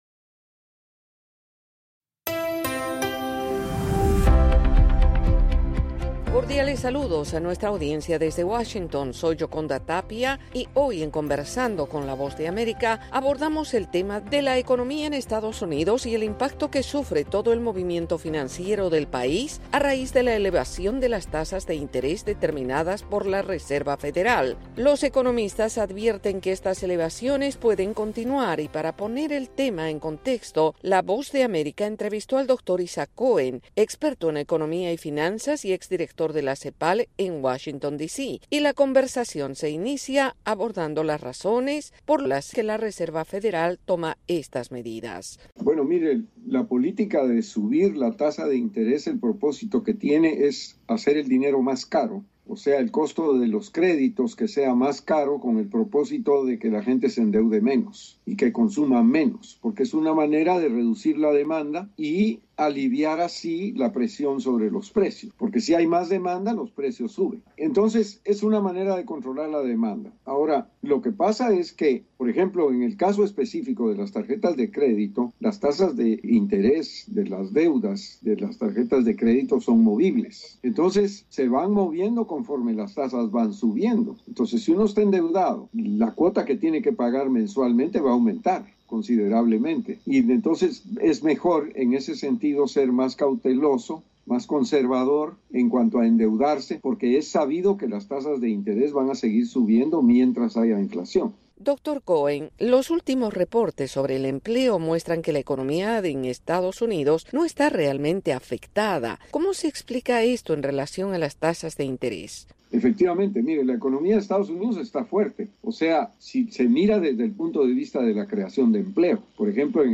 Conversamos con el economista y experto en finanzas